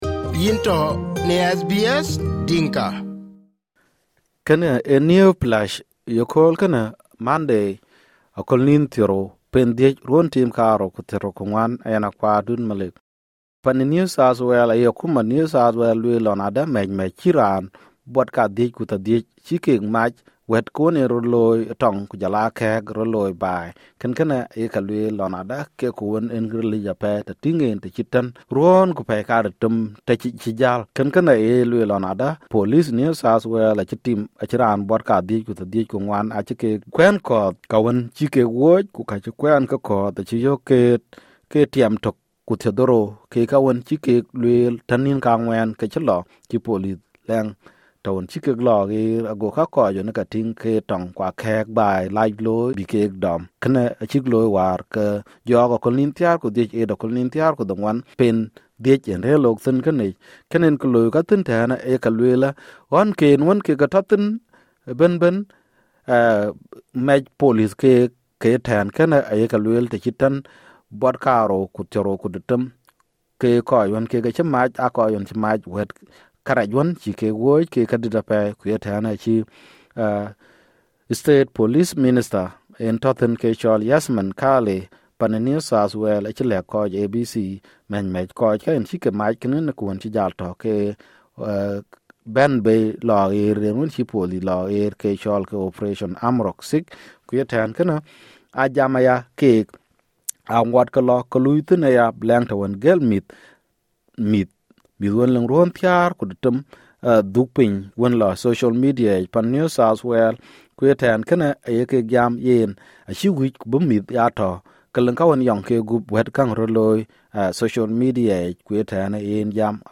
Dinka Newsflash